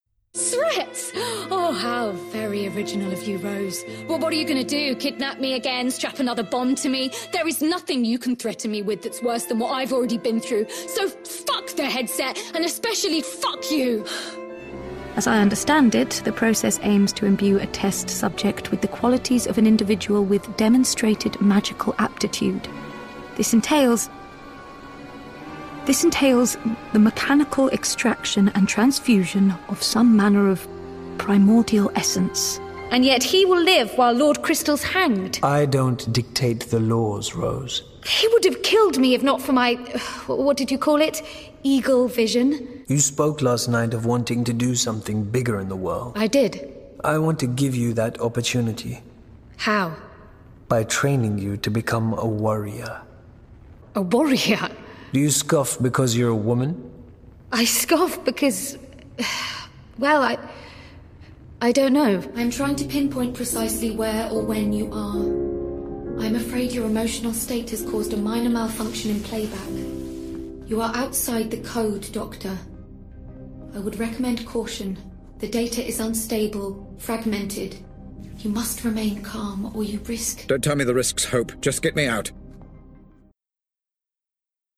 • Native Accent: London
• Home Studio